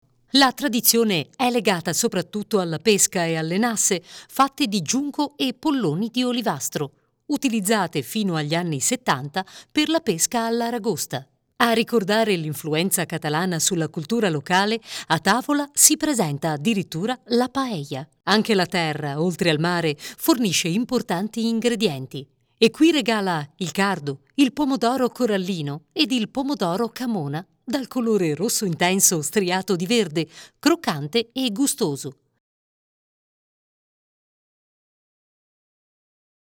Voce matura adatta soprattutto a lavori con finalità documentaristica istituzionale, audioguide, e-learning, alla descrizione di aziende e prodotti e spot pubblicitari.
Voce calda chiara e profonda adatta per documentari, audioguide, e-learning, spot pubblicitari, presentazioni aziendali e di prodotto.
Sprechprobe: Sonstiges (Muttersprache):
My vocal range is 30 - 50 years.